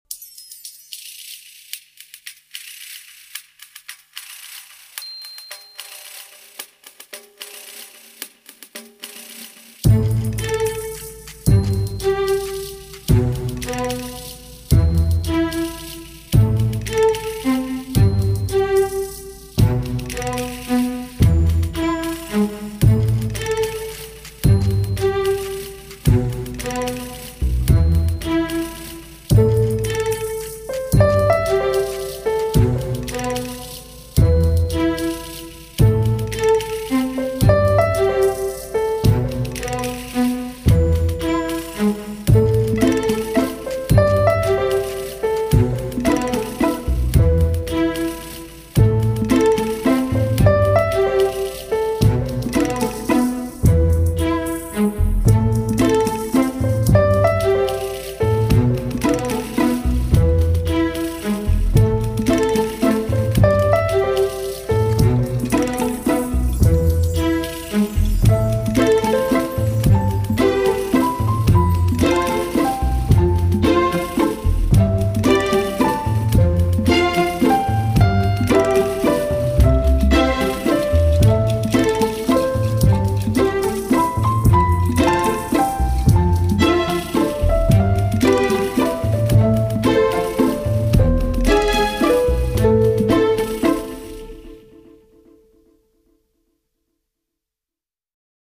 铺陈出轻缓优美的旋律，流泄出法式的浪漫情调.